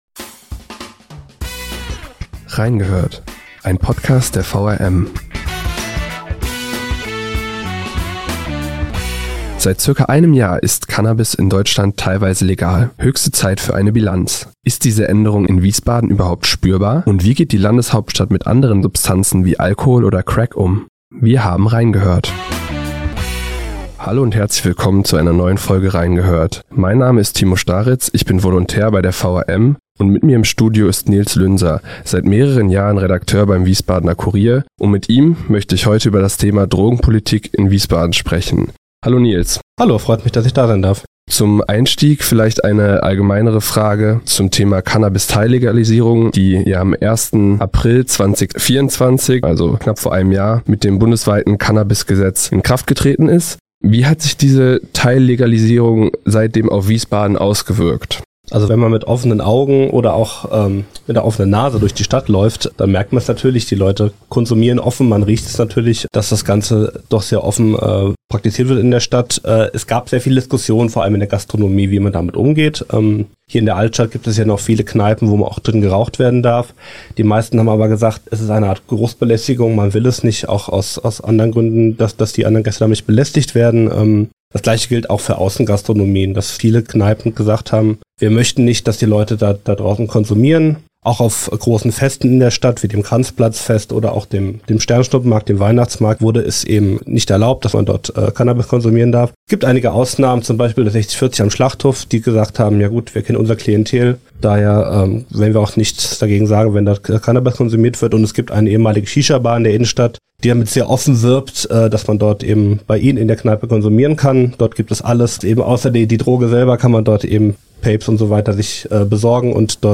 Junge Journalisten blicken gemeinsam mit erfahrenen Reportern auf aktuelle Themen und bewegende Geschichten aus Wiesbaden, Mainz und Darmstadt. Gemeinsam erzählen sie, wie die Geschichten entstehen, die sie für den Wiesbadener Kurier, die Allgemeine Zeitung und das Darmstädter Echo recherchieren.